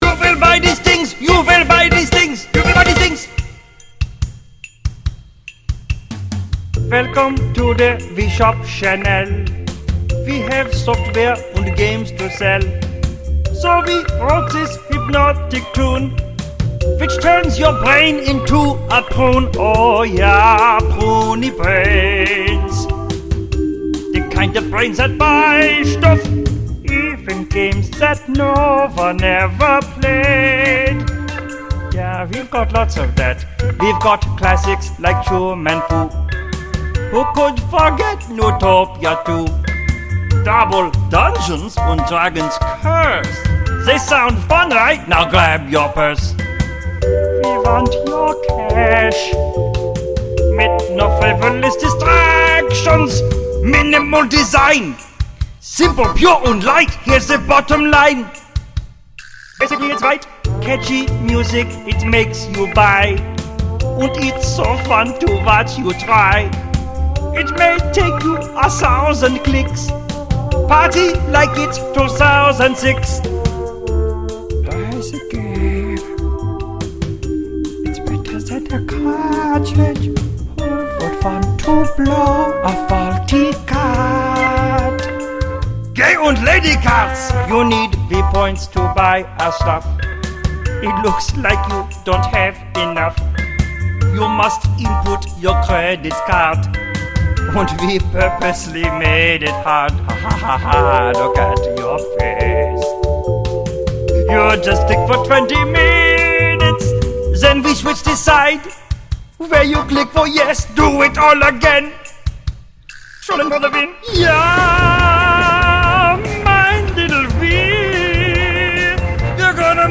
BPM143
Audio QualityCut From Video